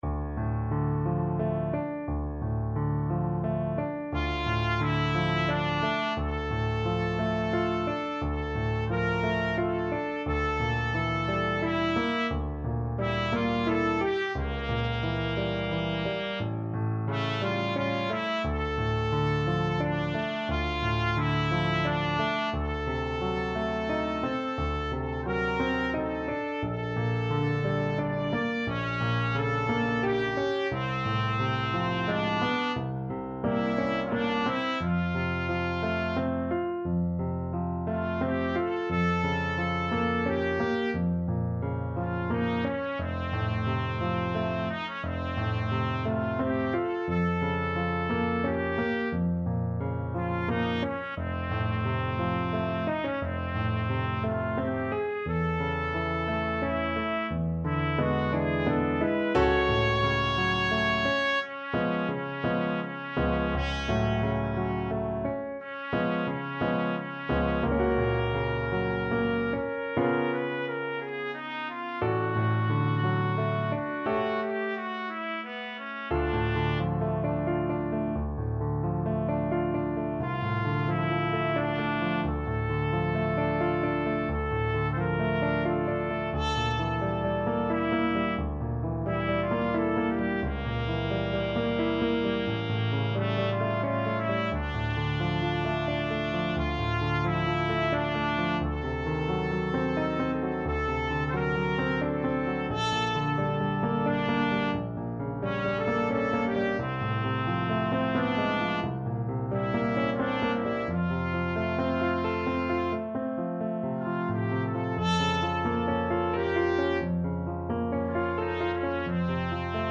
Classical Liszt, Franz Romance, S.169 Trumpet version
Trumpet
D minor (Sounding Pitch) E minor (Trumpet in Bb) (View more D minor Music for Trumpet )
3/4 (View more 3/4 Music)
~ = 88 Malinconico espressivo
Classical (View more Classical Trumpet Music)